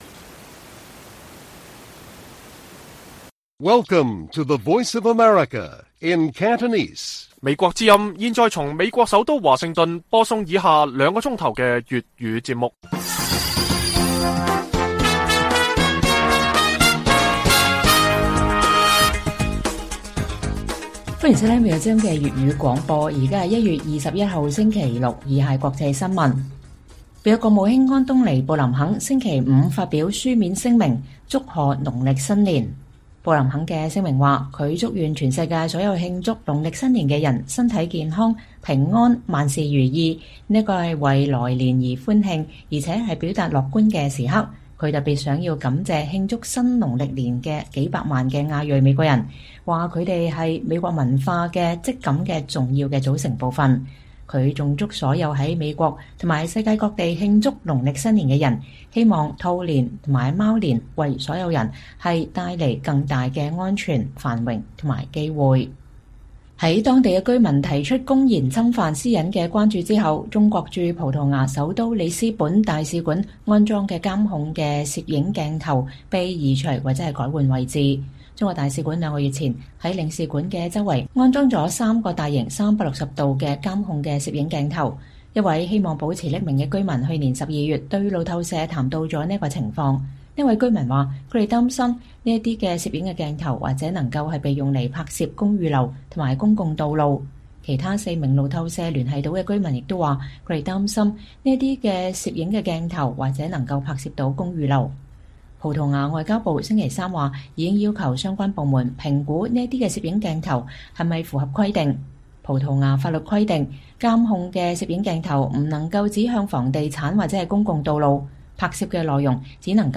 粵語新聞 晚上9-10點：美國國務卿安東尼·布林肯祝賀農曆新年